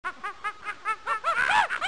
Monkey
monkey.mp3